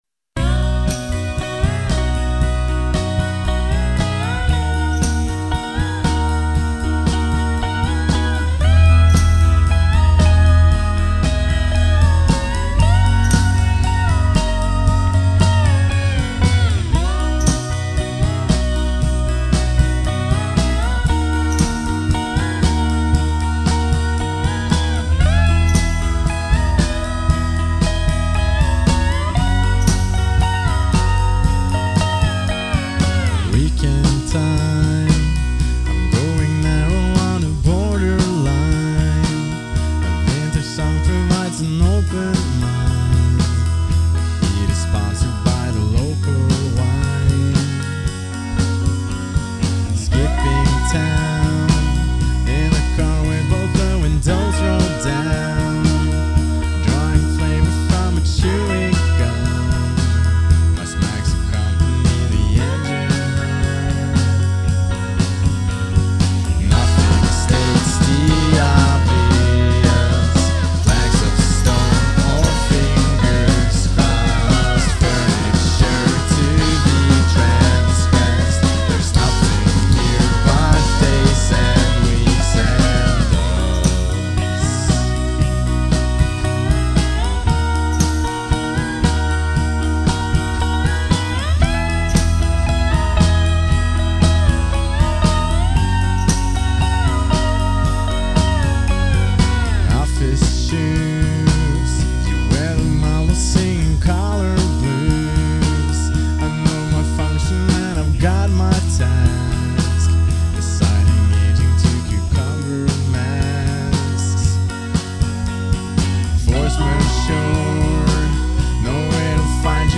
trommer